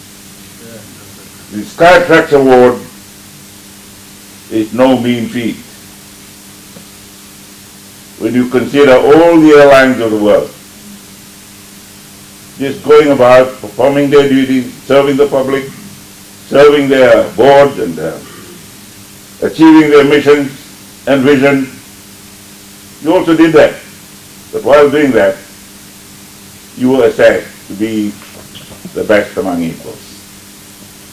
Prime Minister, Sitiveni Rabuka.